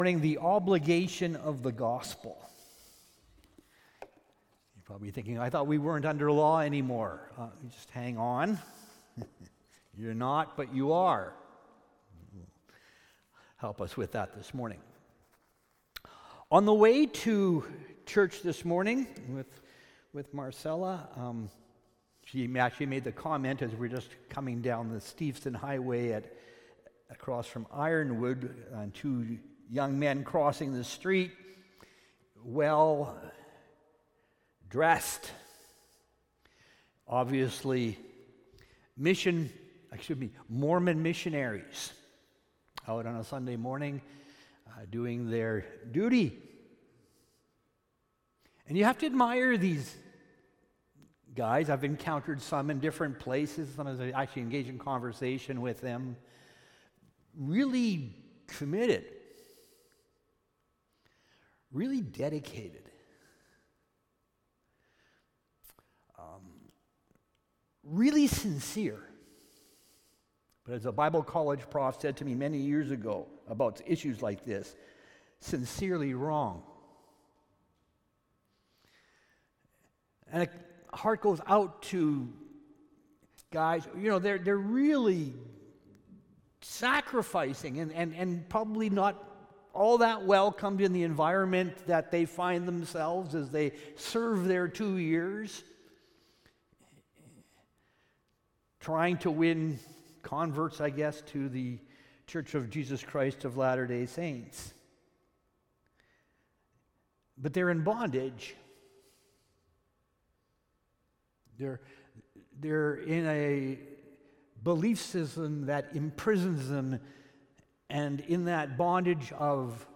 Sermons | Richmond Alliance Church